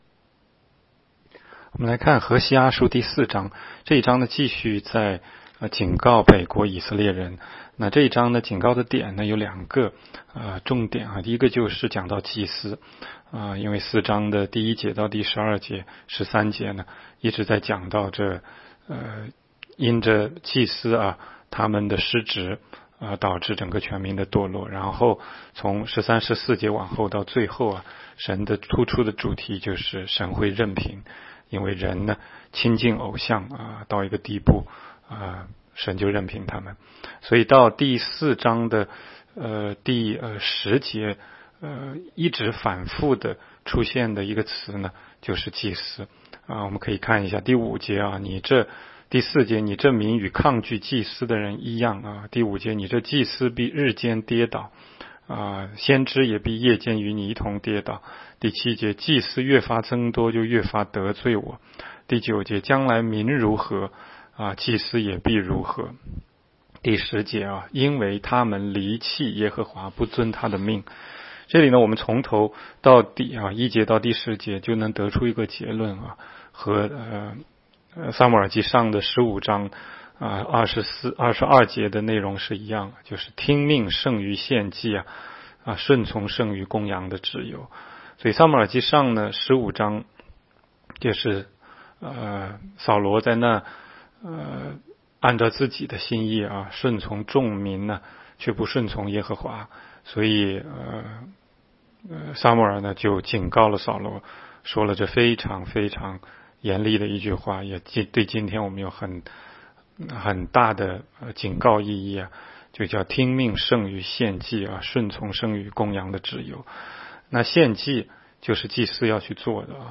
16街讲道录音 - 每日读经 -《何西阿书》4章